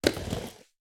SD_SFX_RollerSkate_Step_3.wav